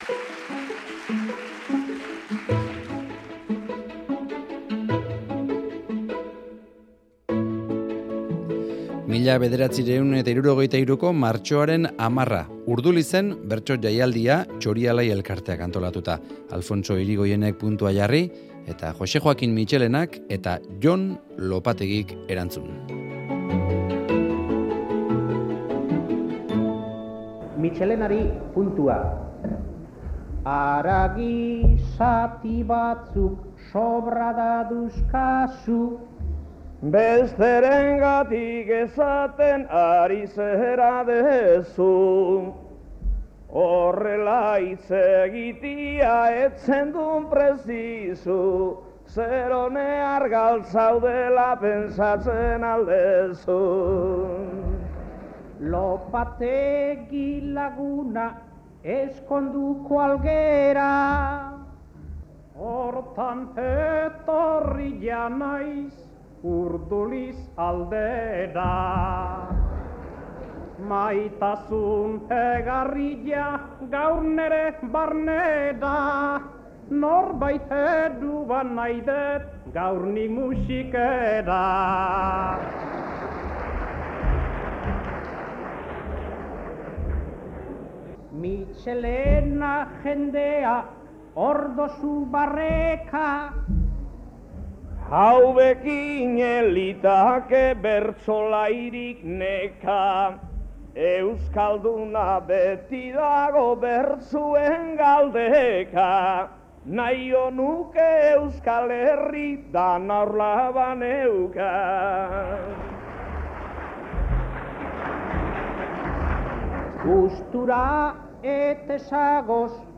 Urdulizen 1963an.